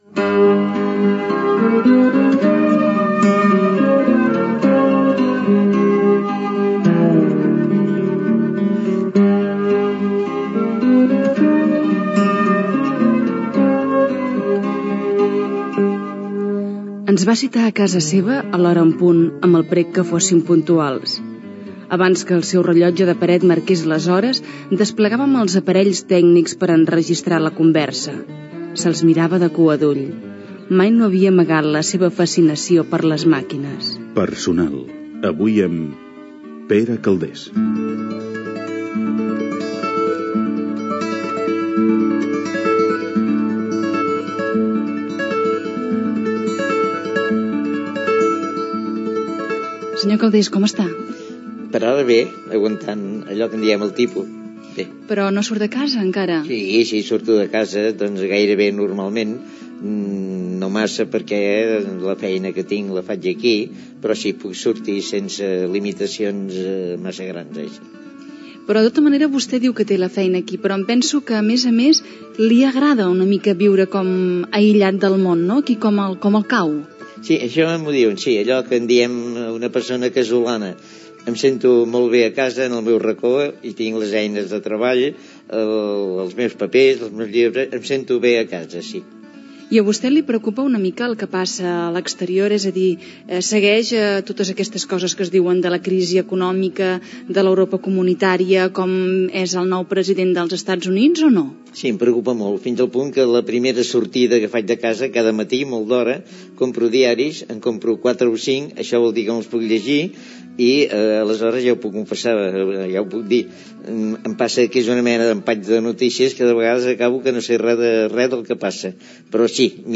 Presentació, indicatiu del programa i primeres preguntes a l'escriptor Pere Calders
Entreteniment
FM